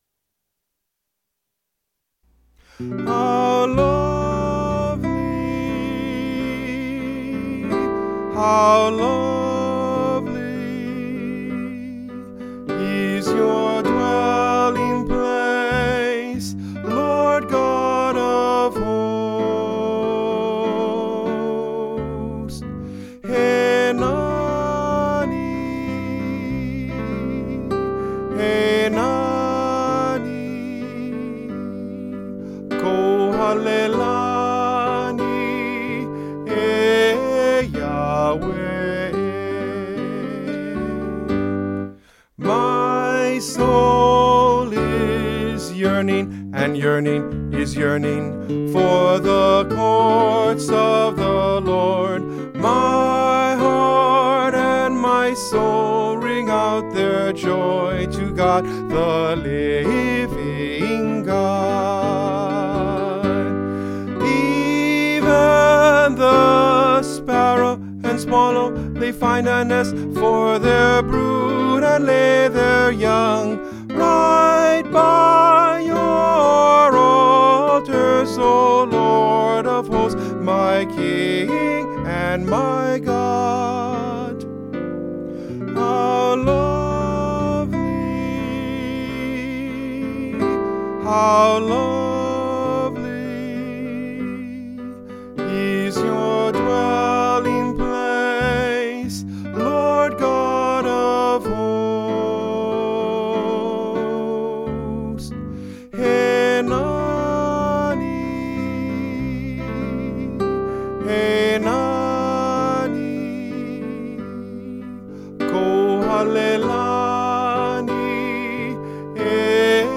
ALL   Instrumental | Downloadable